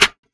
Pornography Snare.wav